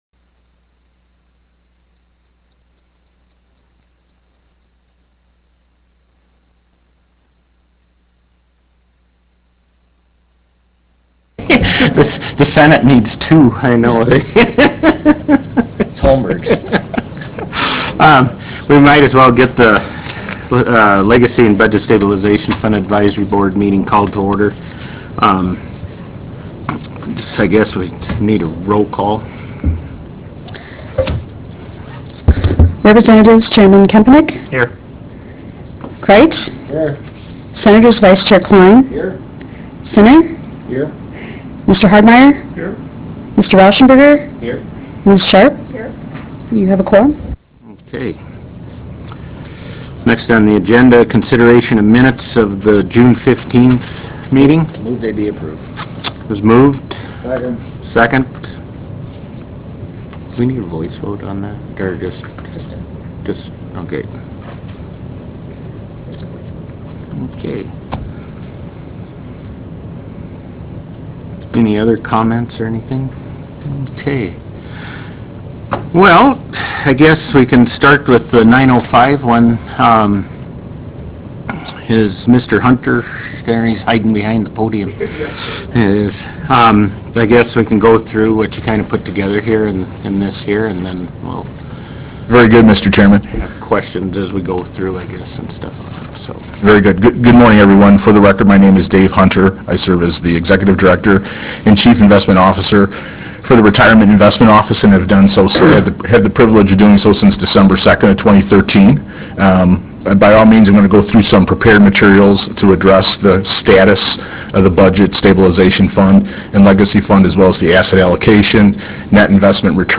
Harvest Room State Capitol Bismarck, ND United States
Meeting Audio